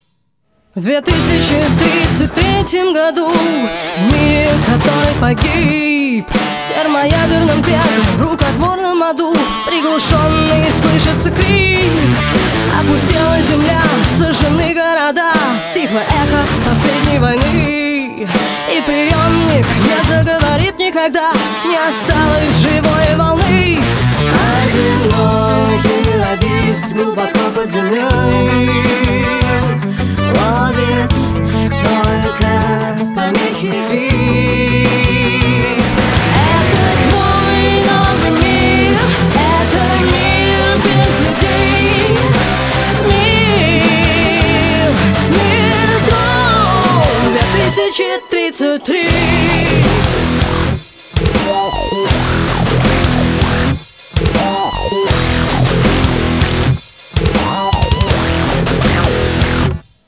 женский вокал
Alternative Metal
русский рок
футуристические